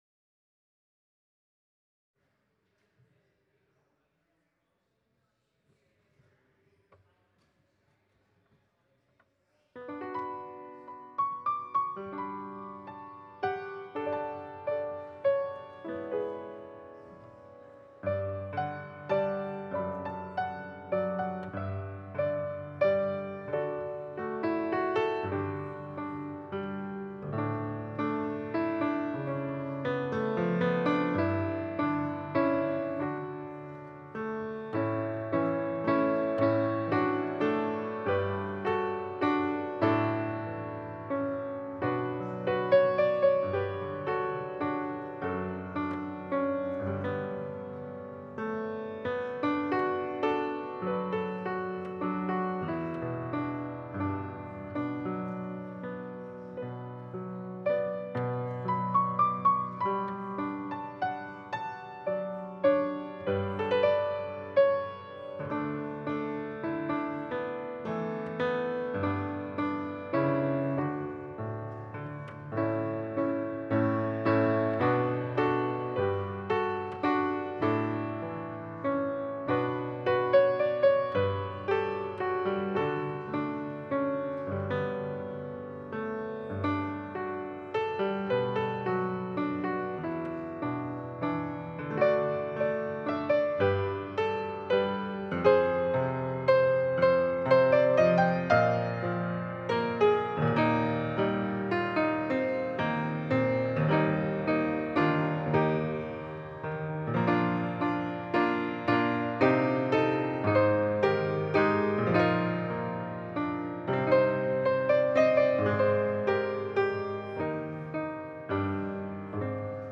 Passage: Matthew 6: 25-34 Service Type: Sunday Service